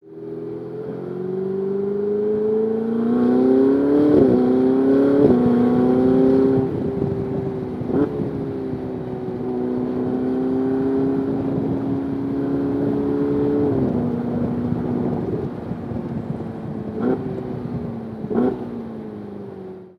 Here are downloadable MP3 samples of the 488’s new turbo V8 in various scenarios. Still sounds awesome, but also very different than ever before.
Ferrari-New-V8-Upshifts-and-downshifts-open-air.mp3